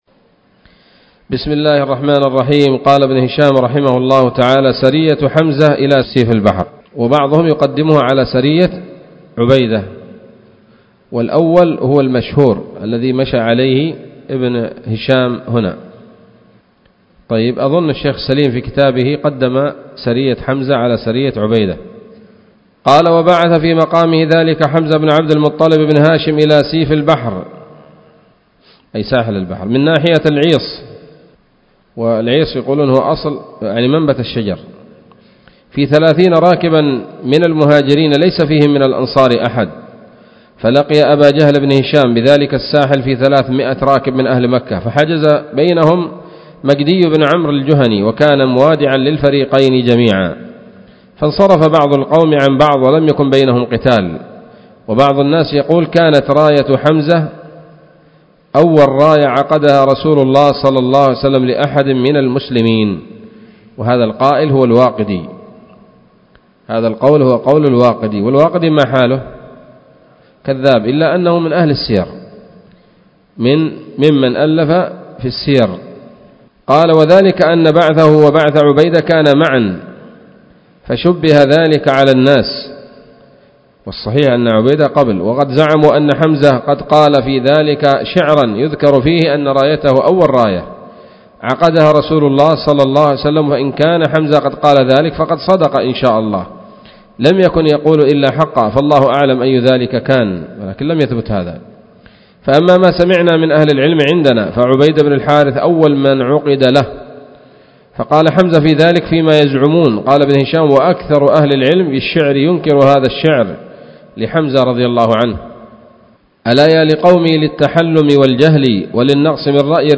الدرس الرابع بعد المائة من التعليق على كتاب السيرة النبوية لابن هشام